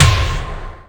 Kick_02.wav